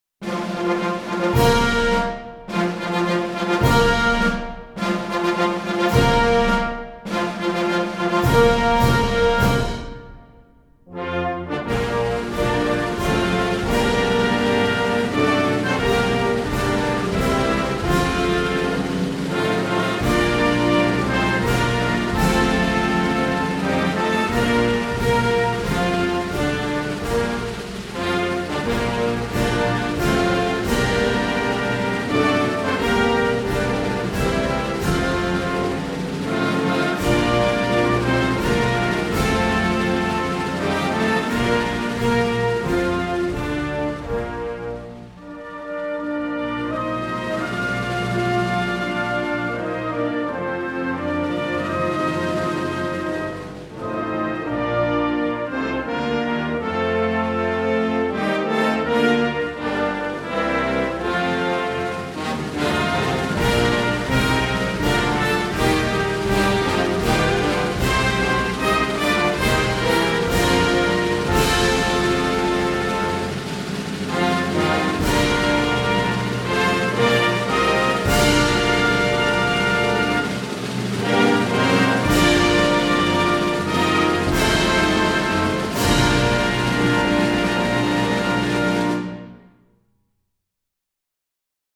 Four_Ruffles_and_Flourishes_Star_Spangled_Banner.mp3